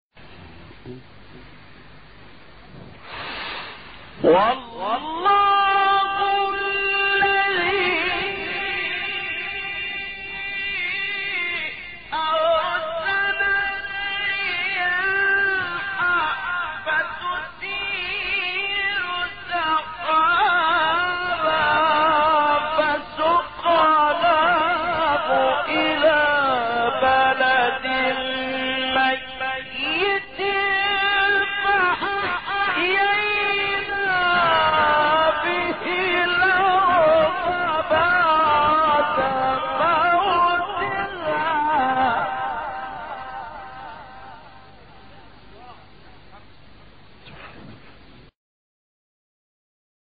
گروه شبکه اجتماعی: نغمات صوتی با صدای قاریان برجسته مصری ارائه می‌شود.